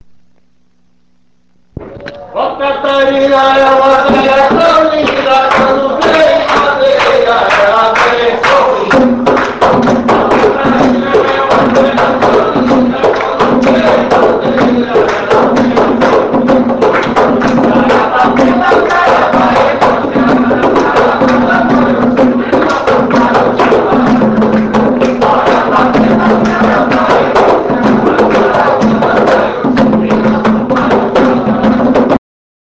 Todos os pontos aqui apresentados foram gravados ao vivo.